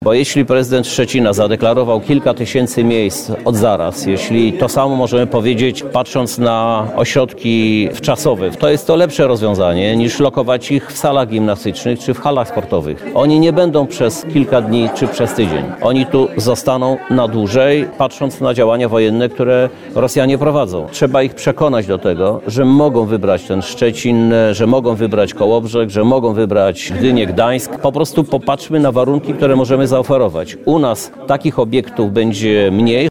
– Uchodźcy z Ukrainy, którzy przekraczają polską granicę, powinni od razu wiedzieć gdzie jadą – o większą sprawność działań administracji rządowej w tej sprawie zaapelował prezydent Lublina Krzysztof Żuk.